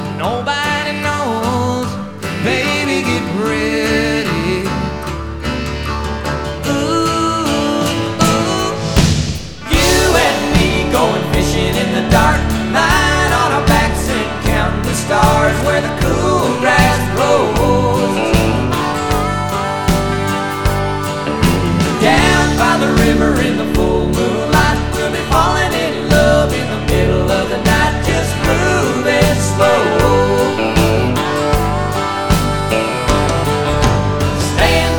Жанр: Рок / Кантри